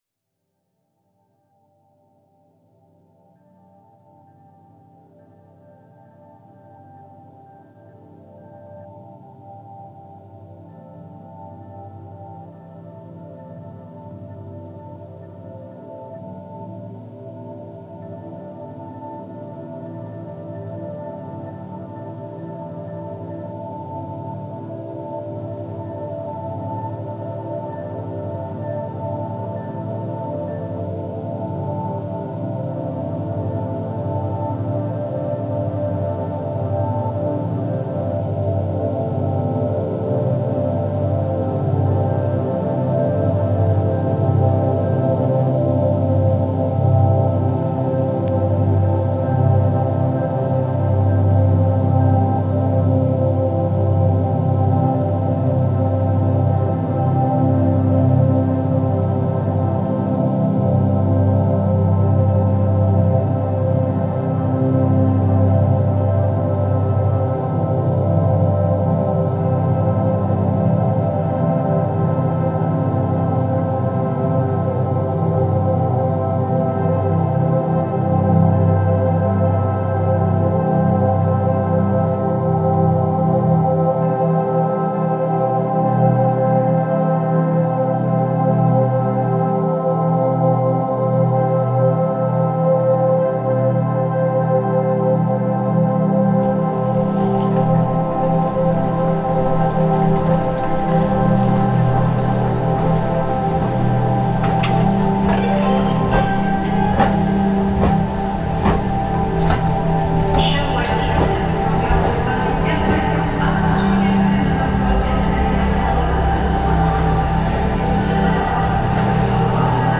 Gare de Lyon, Paris reimagined